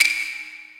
drum-hitwhistle.wav